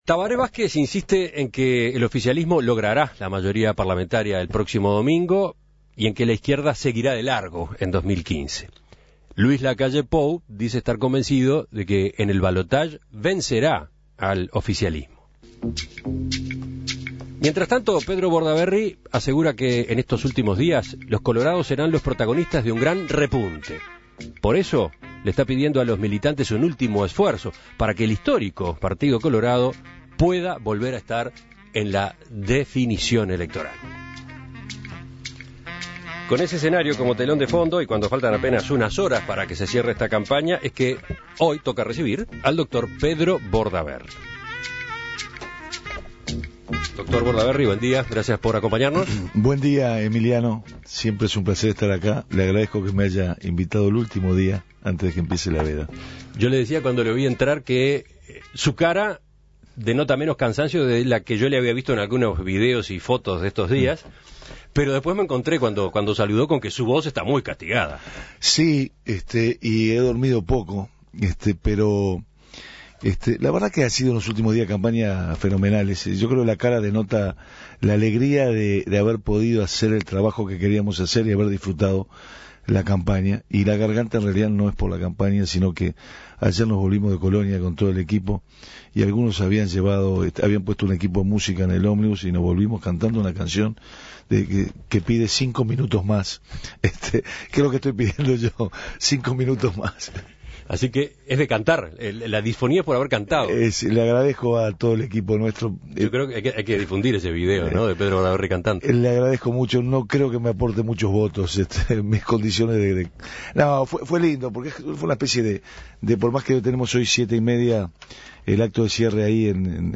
En estas elecciones, Pedro Bordaberry apunta a superar Lacalle Pou y disputar el balotaje en noviembre, y así consolidar a su coalición. En diálogo con En Perspectiva, el candidato colorado habló sobre el futuro del partido y las resoluciones que tomará el domingo luego de conocerse los resultados.